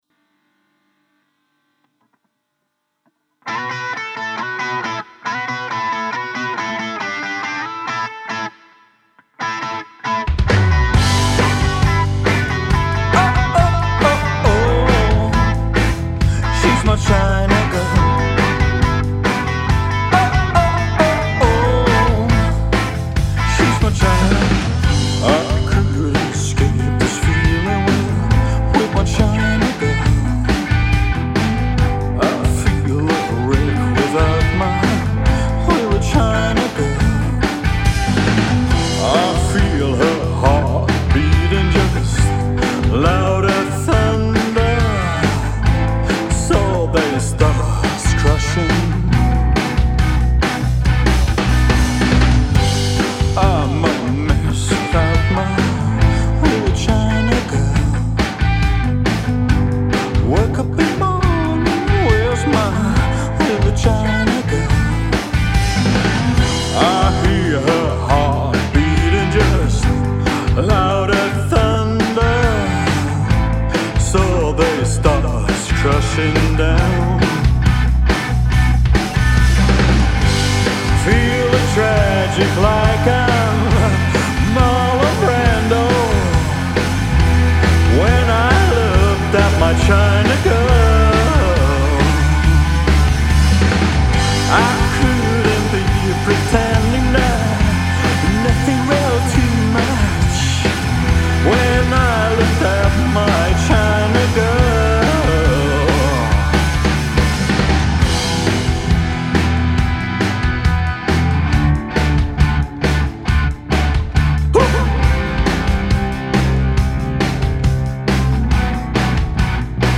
Rock, med en stank av billy och en doft av Tarantinosfilmer.
• Coverband